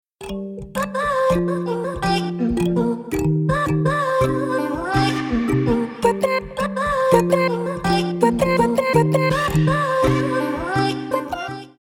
• Качество: 320, Stereo
dance
без слов
club
Melodic
romantic